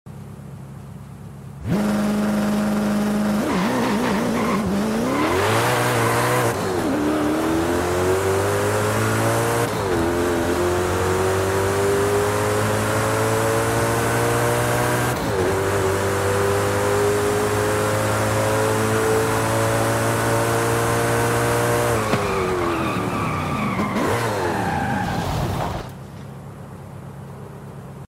2002 Koenigsegg CC8S Launch Control sound effects free download
2002 Koenigsegg CC8S Launch Control & Sound - Forza Horizon 5